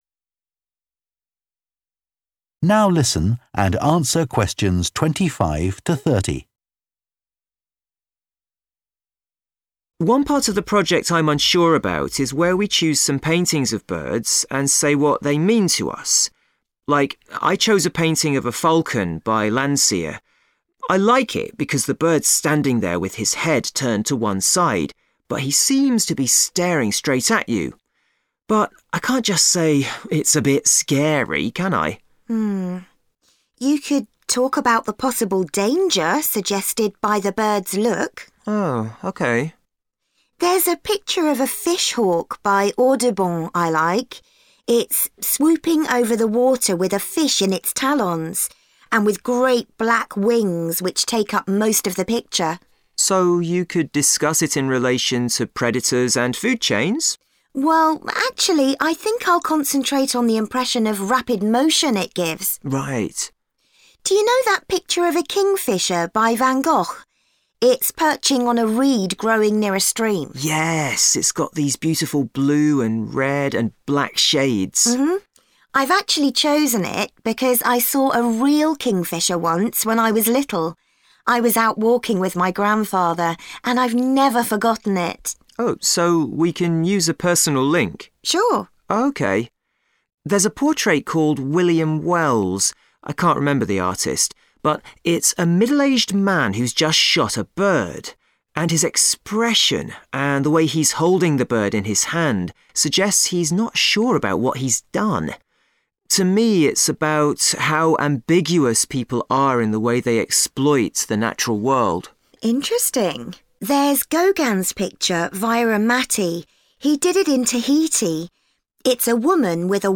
Which personal meaning do the students decide to give to each of the following pictures?